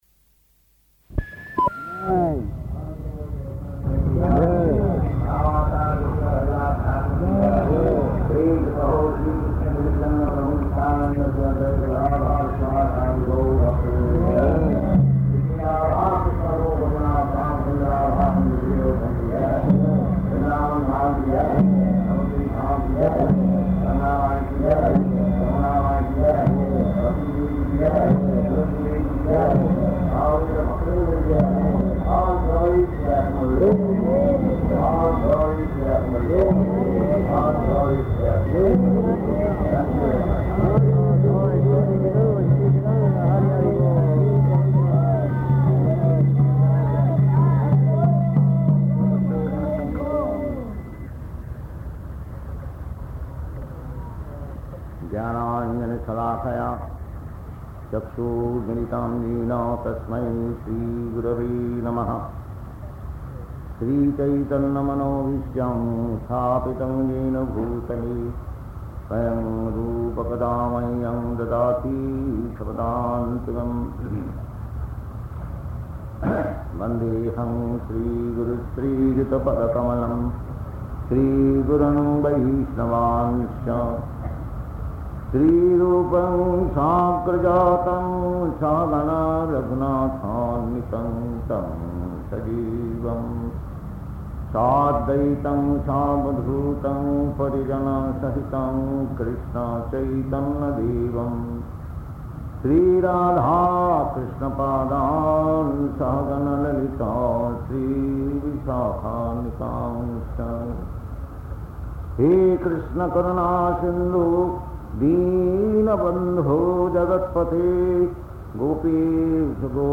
Bhagavad-gītā 7.7 --:-- --:-- Type: Bhagavad-gita Dated: April 1st 1971 Location: Bombay Audio file: 710401BG-BOMBAY.mp3 Prabhupāda: [ prema-dhvani ] Thank you very much.